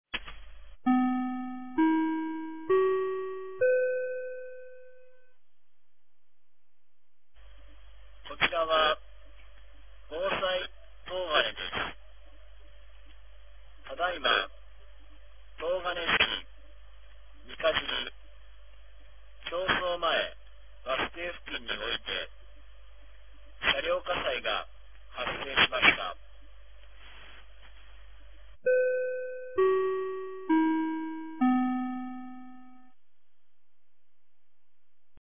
2025年01月05日 14時33分に、東金市より防災行政無線の放送を行いました。